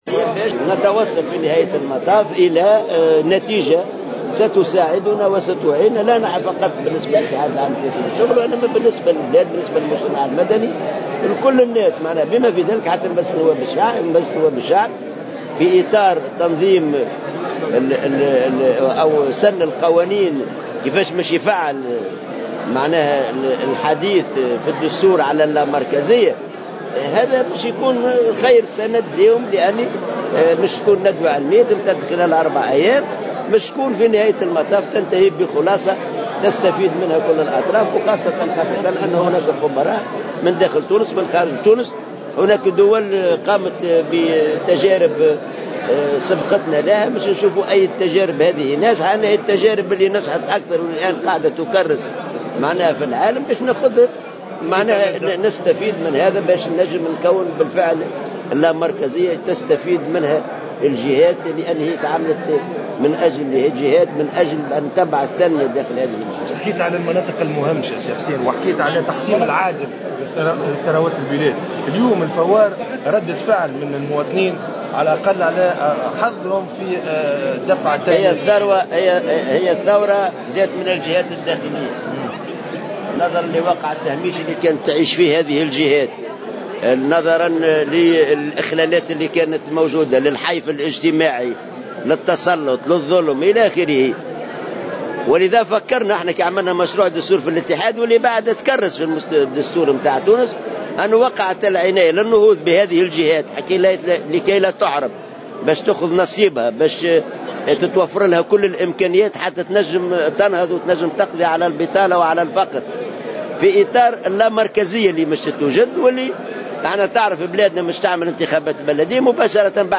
قال حسين العباسي الأمين العام للاتحاد العام التونسي للشغل إن المنظمة الشغيلة تدفع نحو لامركزية البلاد في تصريح لمراسل "جوهرة أف أم" على هامش ملتقى علمي اليوم الخميس 14 ماي 2015.